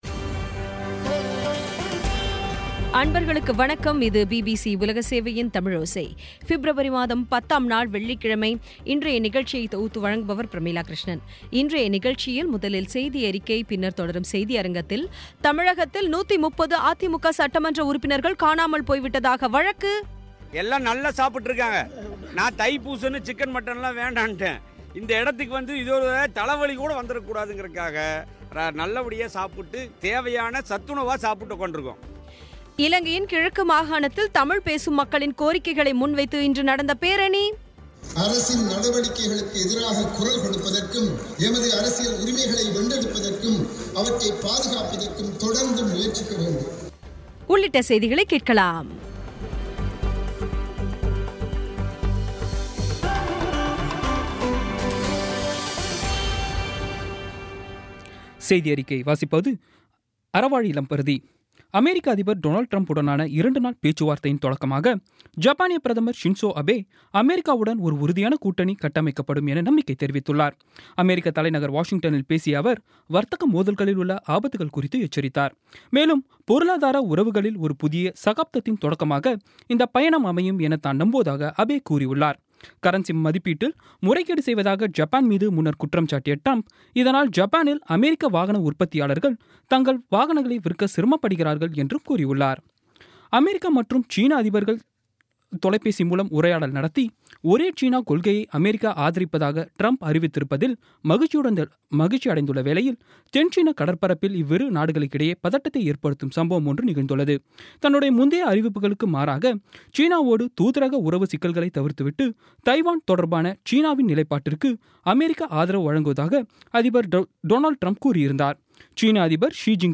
இன்றைய தமிழோசையில், முதலில் செய்தியறிக்கை, பின்னர் தொடரும் செய்தியரங்கத்தில்,தமிழகத்தில் 130 அதிமுக சட்டமன்ற உறுப்பினர்கள் காணாமல் போய்விட்டதாக வழக்கு தொடரப்பட்டுள்ளது குறித்த செய்தி இலங்கையின் கிழக்கு மாகாணத்தில் தமிழ் பேசும் மக்களின் கோரிக்கைகளை முன்வைத்து இன்று நடந்த பேரணி